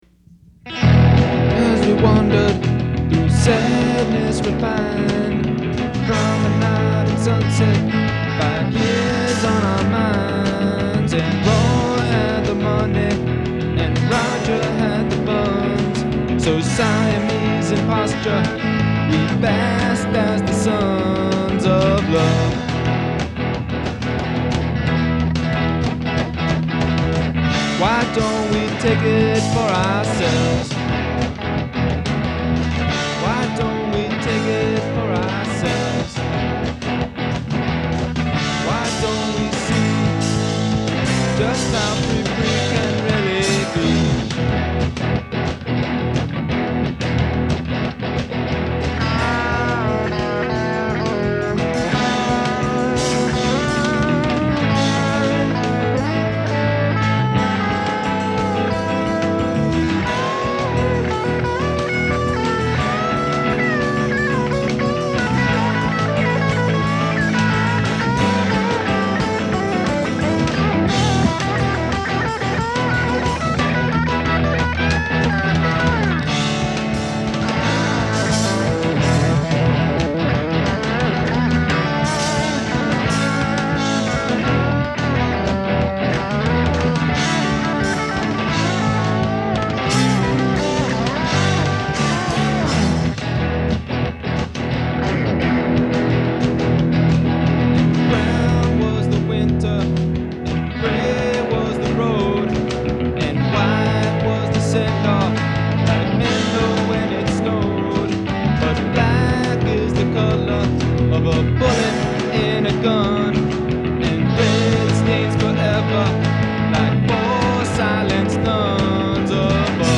Risley Sub-Basement Edition
guitar, vocals
drums
bass